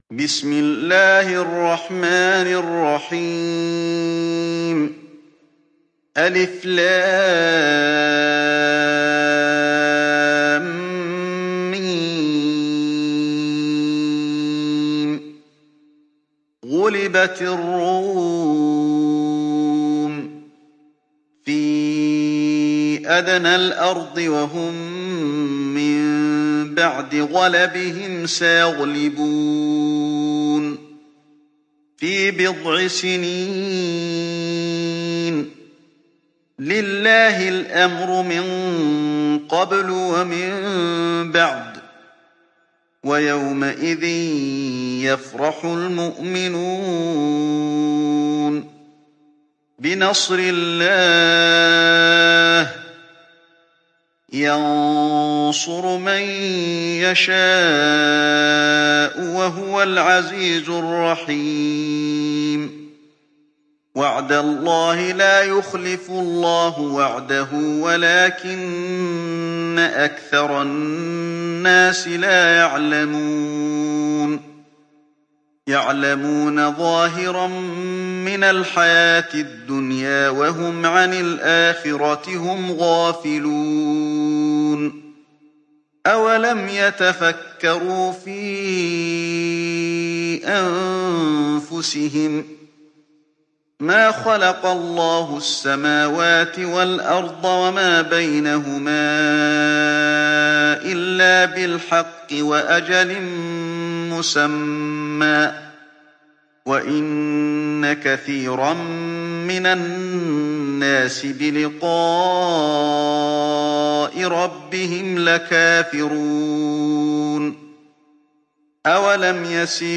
تحميل سورة الروم mp3 بصوت علي الحذيفي برواية حفص عن عاصم, تحميل استماع القرآن الكريم على الجوال mp3 كاملا بروابط مباشرة وسريعة